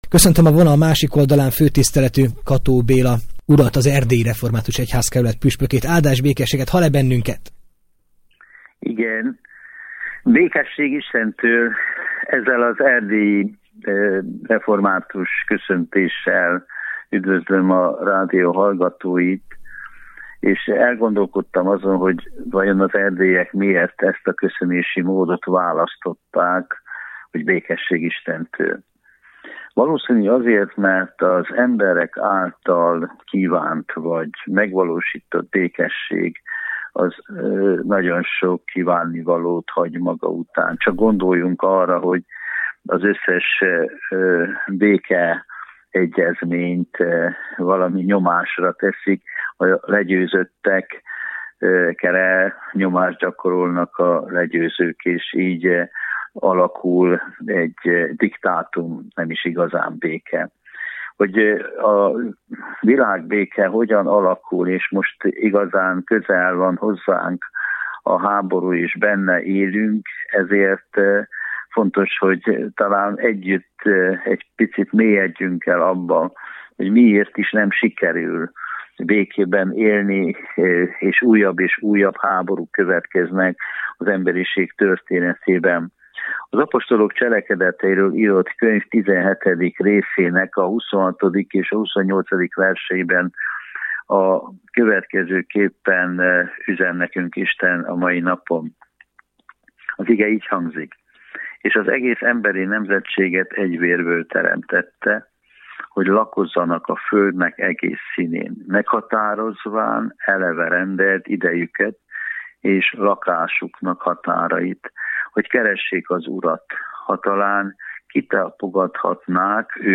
Bátorító Üzenetek - Kató Béla, püspök